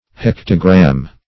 Meaning of hektogram. hektogram synonyms, pronunciation, spelling and more from Free Dictionary.
Hektogram \Hek"to*gram\